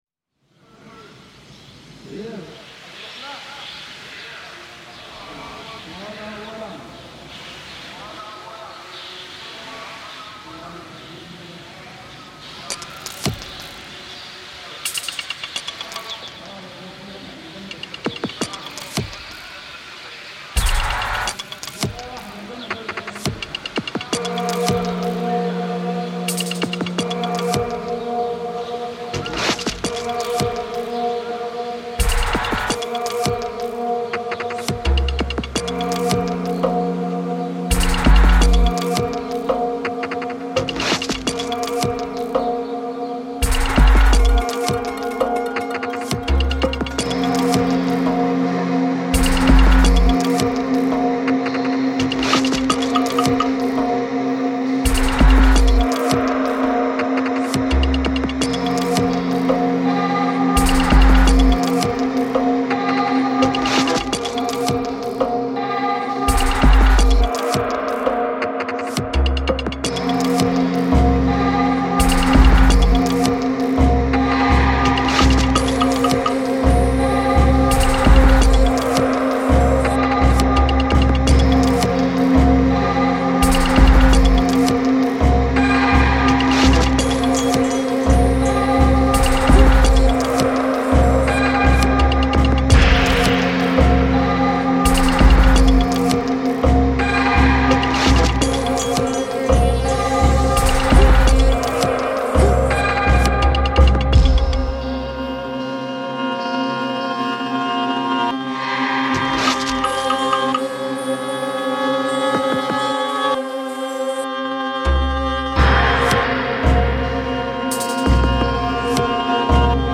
' features the field recording from the Luxor Temple, Egypt.